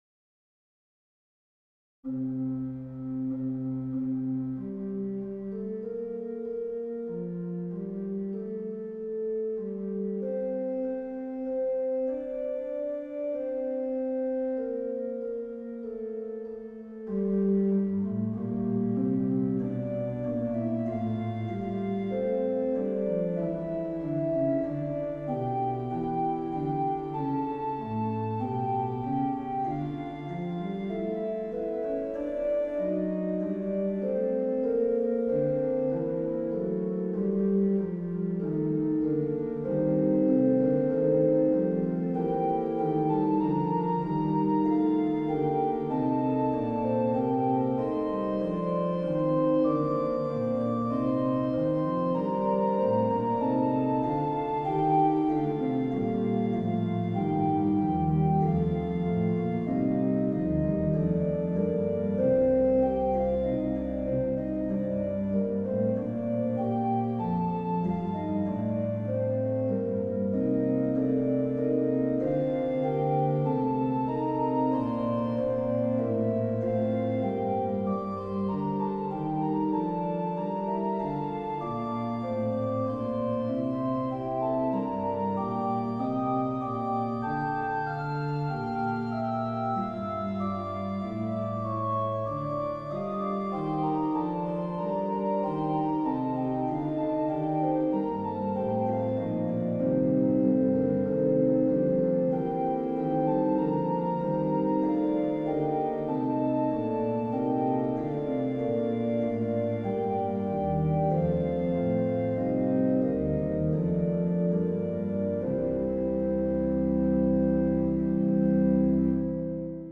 mp3 organ